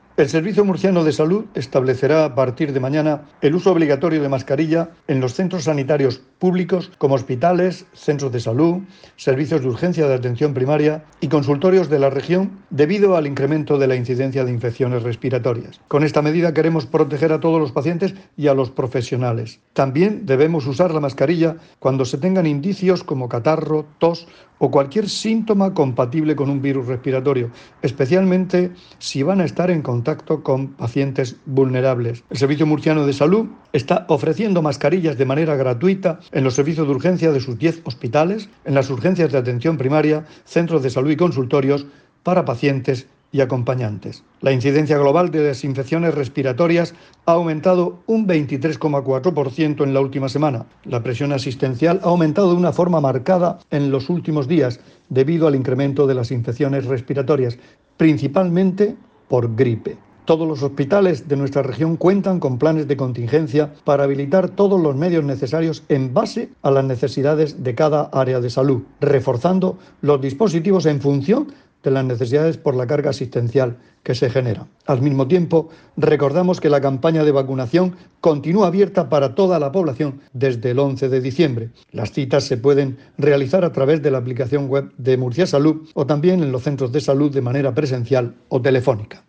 Declaraciones del consejero de Salud, Juan José Pedreño, sobre la nueva medida de obligatoriedad del uso de mascarilla en centros sanitarios.